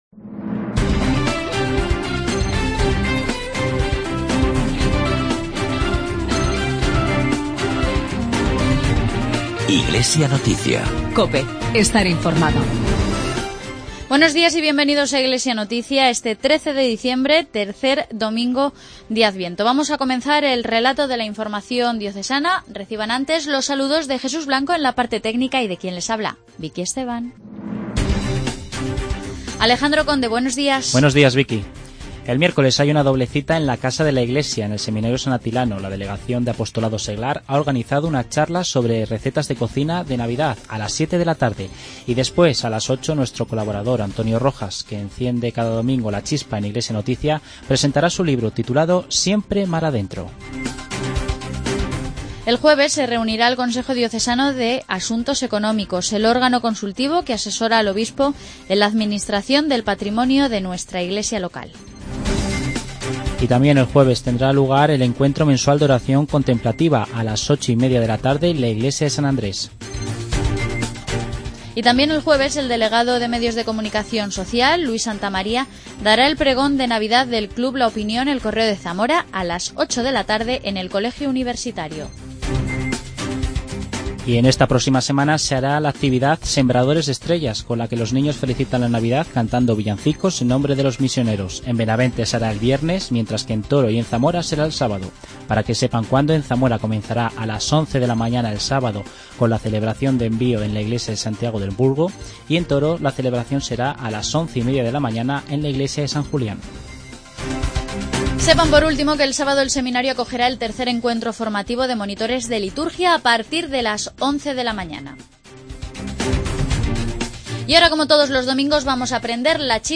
Informativo diocesano.